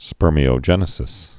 (spûrmē-ō-jĕnĭ-sĭs)